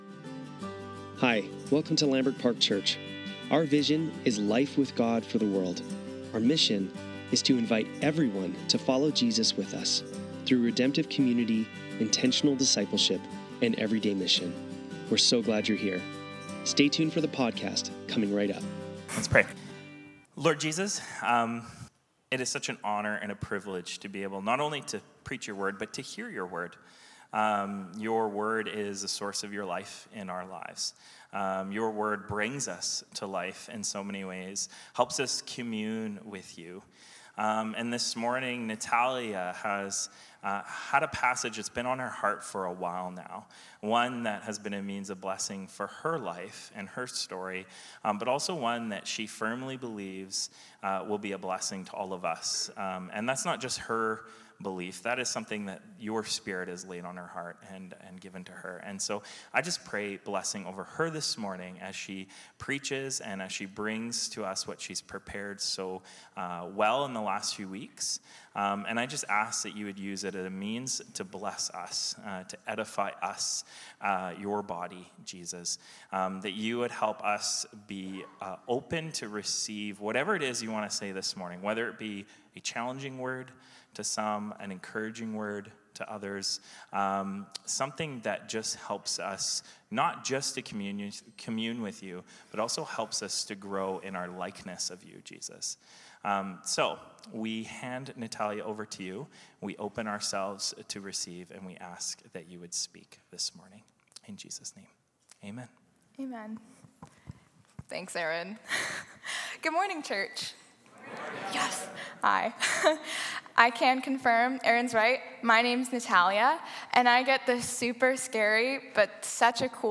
Lambrick Sermons | Lambrick Park Church